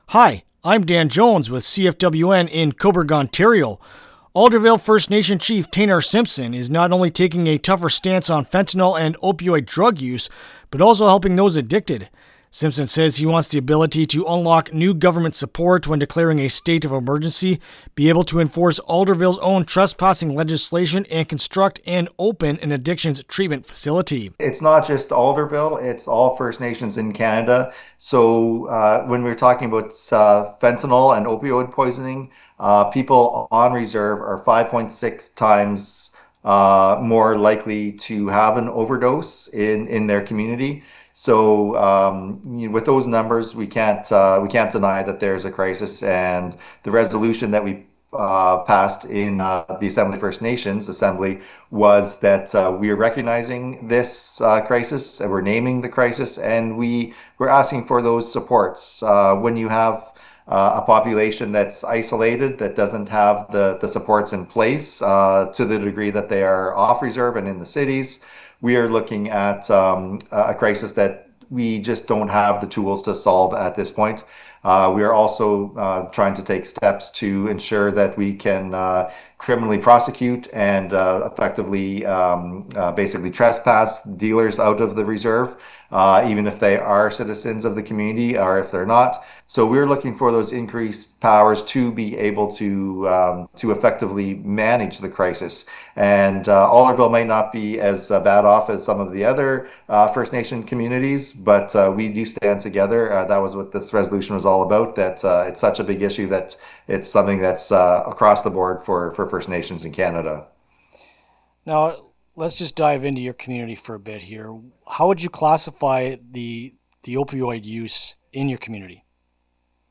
Chief-Simpson-Opioid-Interview-LJI.mp3